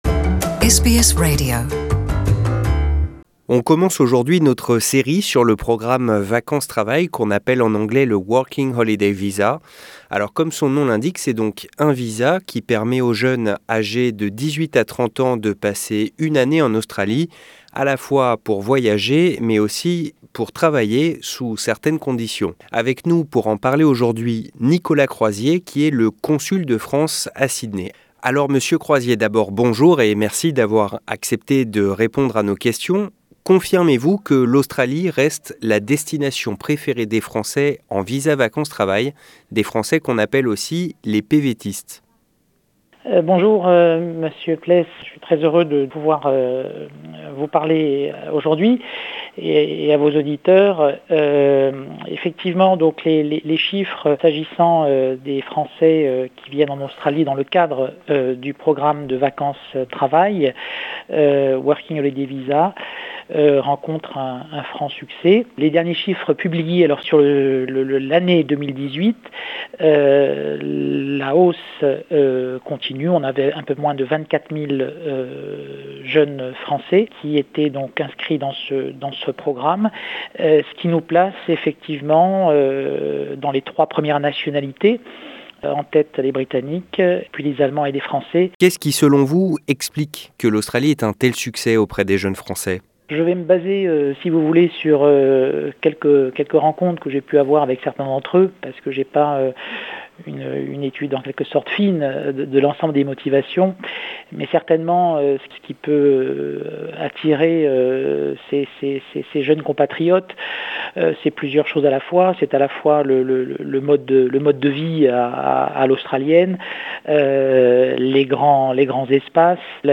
Notre entretien avec Nicolas Croizier, le consul de France à Sydney, qui distille ses conseils pour bien réussir son année en Australie dans le cadre du programme vacances travail.